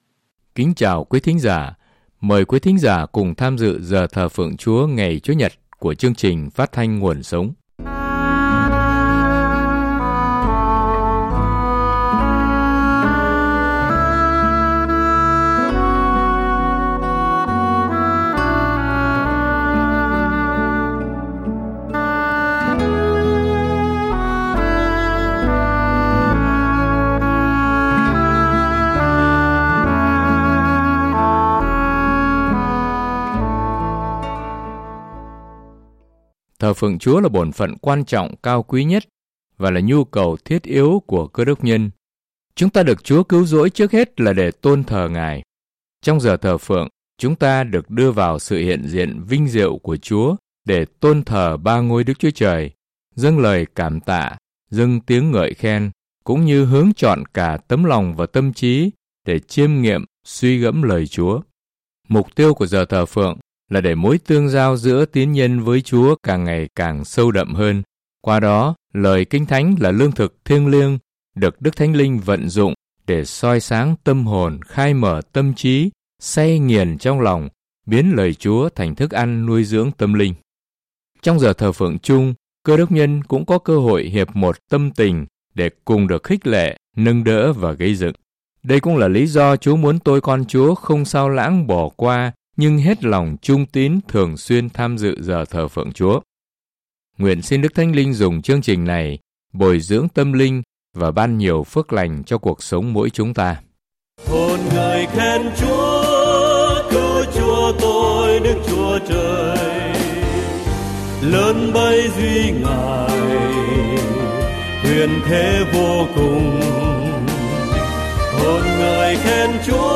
Thờ Phượng Giảng Luận